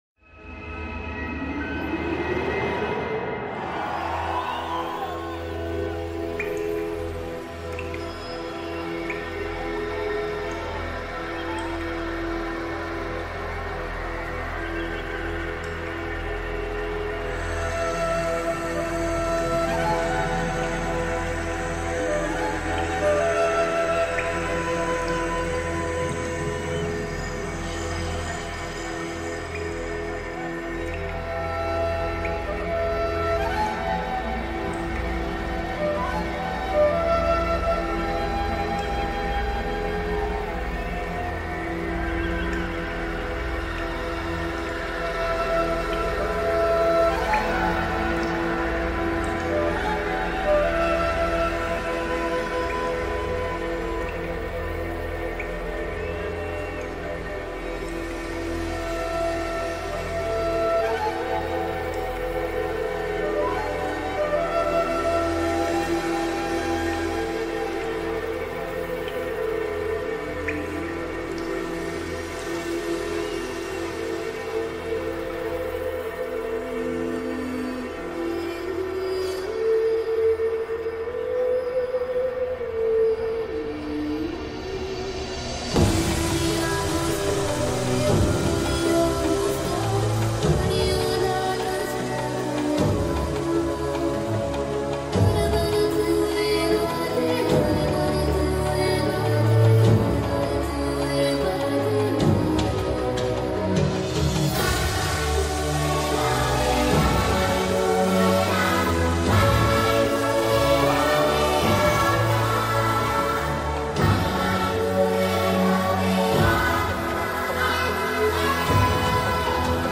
Genre: Trance